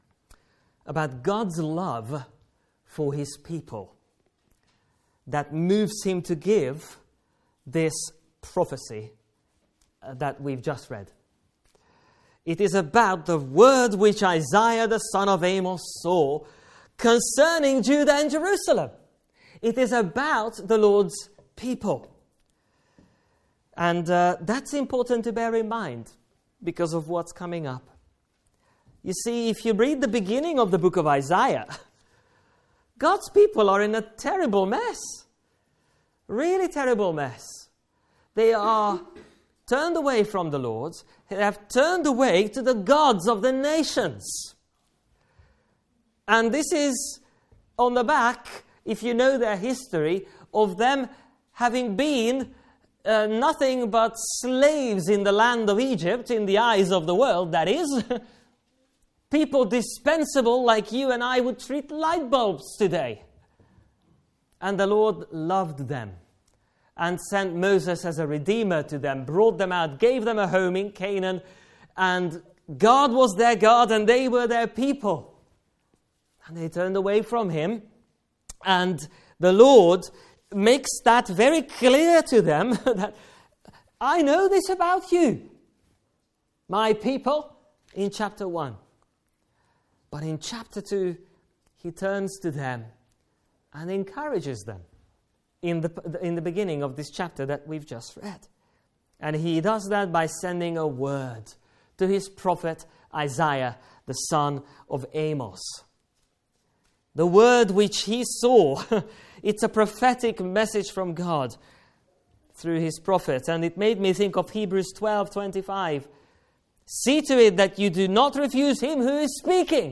Passage: Isaiah 2: 1-11 Service Type: Afternoon Service